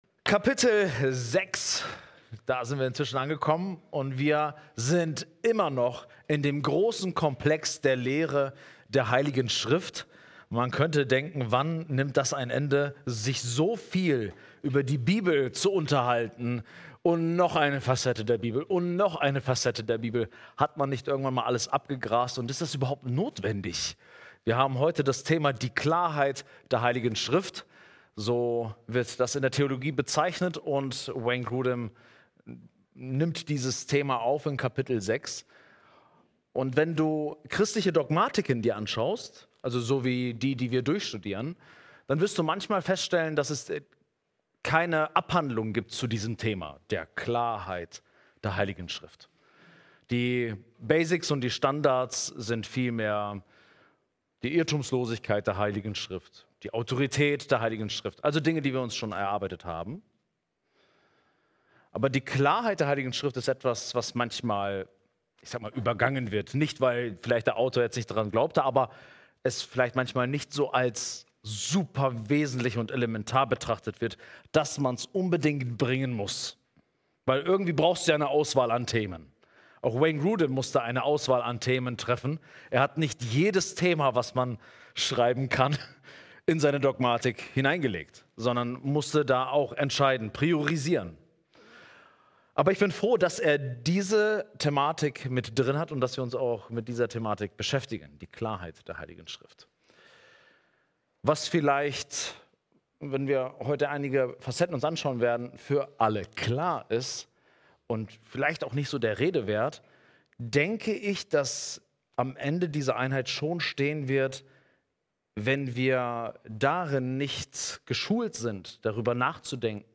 Predigt: Das Feuer des Geistes entfachen!